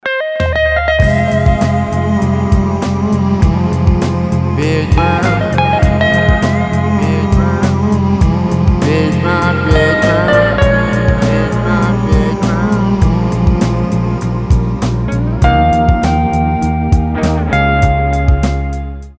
• Качество: 320, Stereo
мужской голос
русский рок